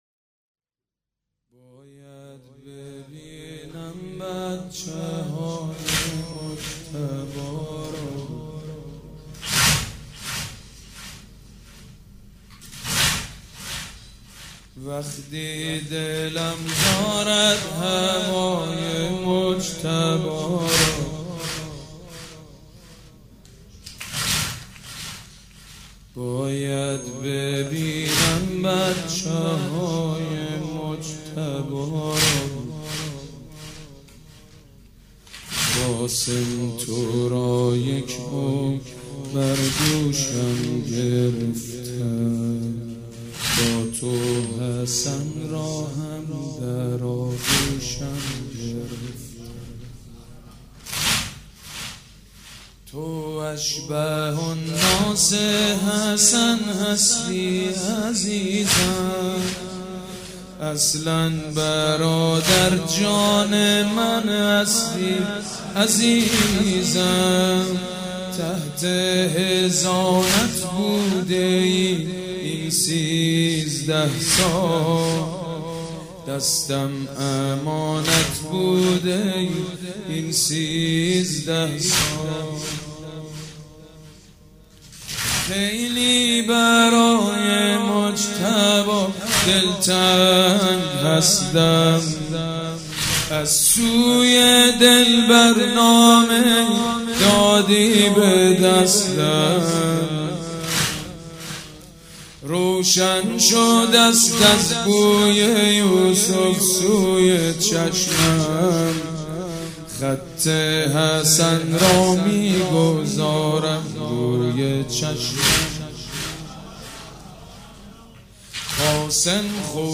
شب ششم محرم الحرام‌ جمعه ۱6 مهرماه ۱۳۹۵ هيئت ريحانة الحسين(س)
سبک اثــر واحد مداح حاج سید مجید بنی فاطمه
مراسم عزاداری شب ششم